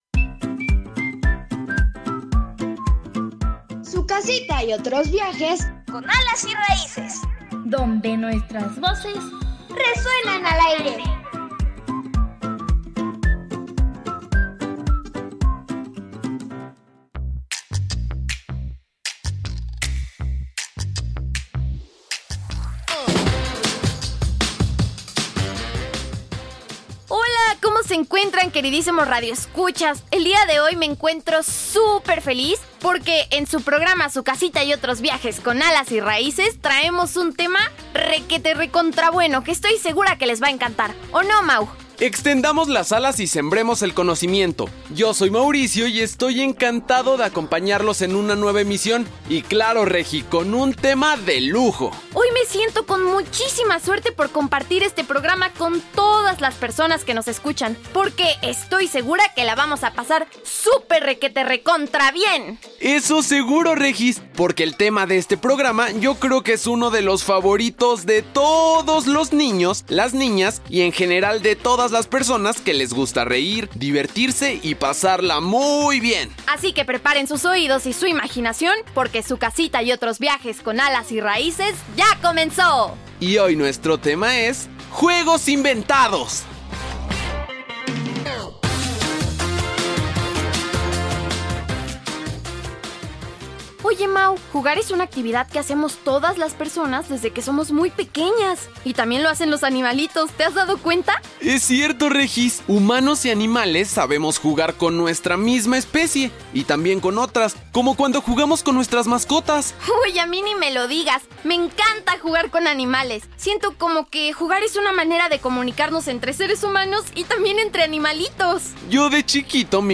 Te has preguntado, ¿qué es lo más divertido de inventar juegos? niñas y niños responden a esta pregunta.